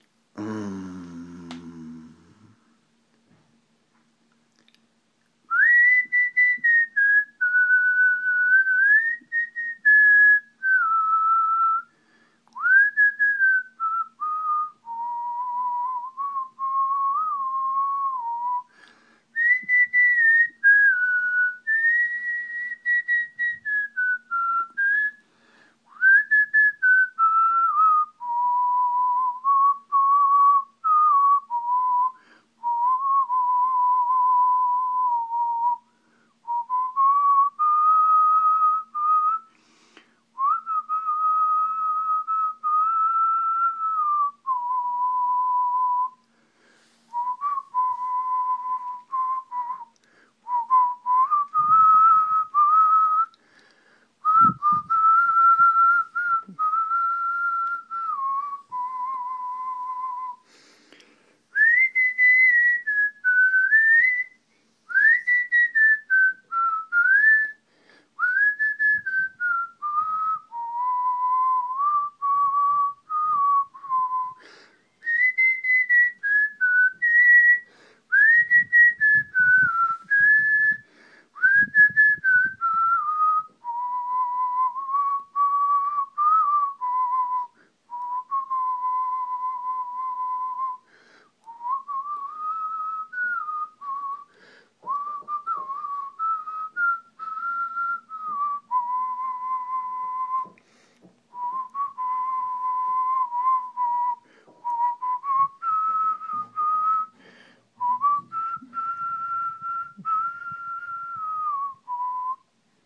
Hollow and Desperately Imperfect Whistled Song